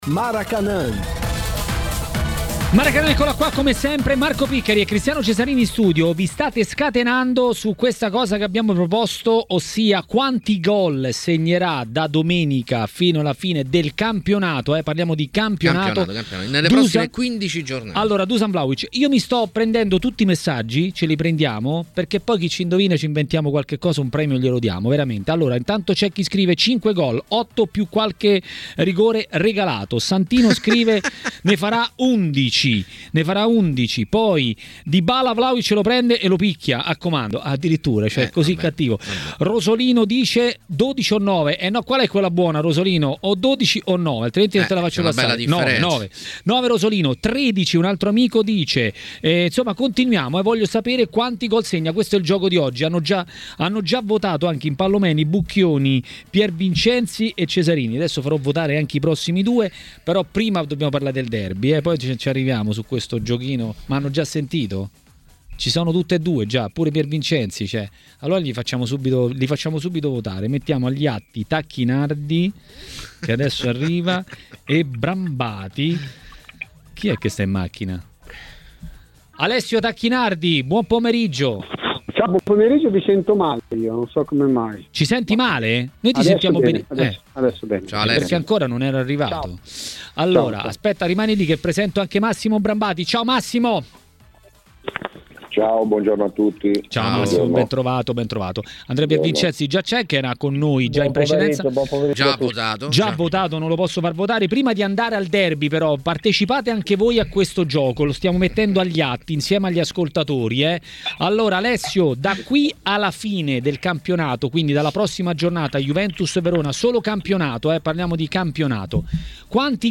L'ex calciatore e tecnico Alessio Tacchinardi a TMW Radio, durante Maracanà, ha parlato della ripresa del campionato.